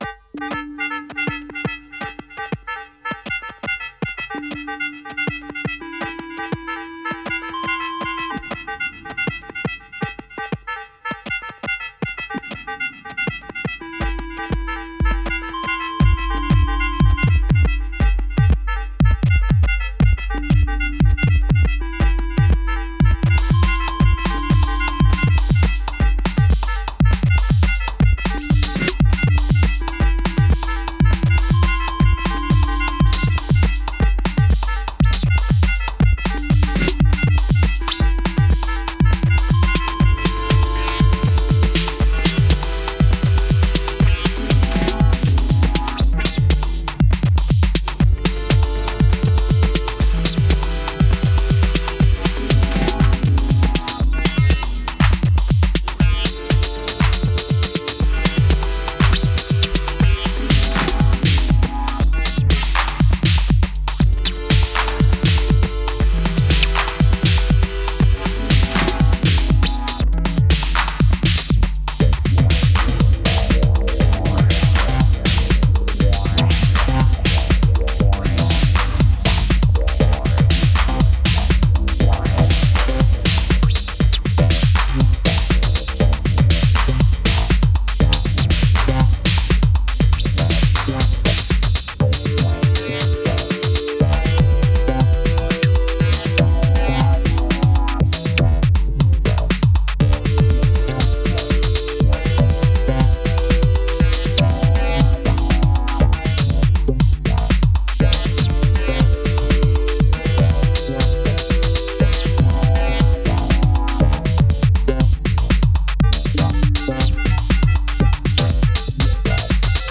Today’s entry for the chord progression game with current grouping: DN, OT, TR8S, Rev2.